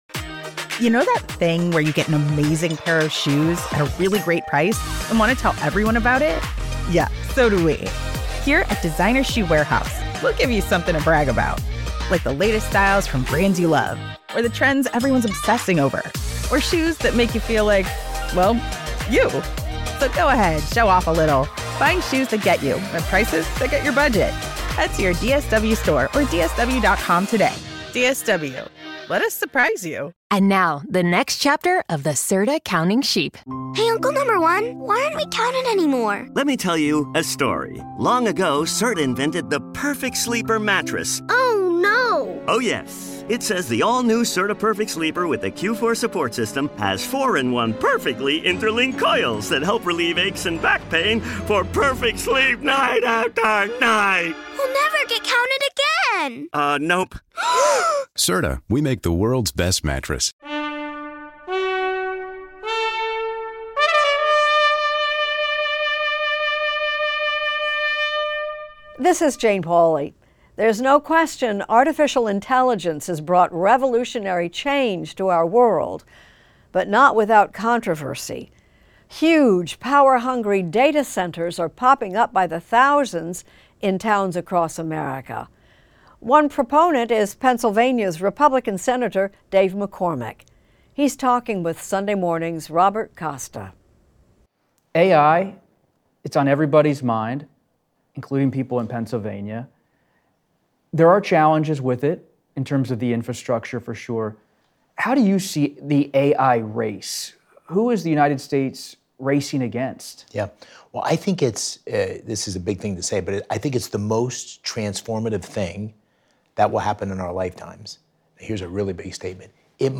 Extended Interview: Sen. Dave McCormick on AI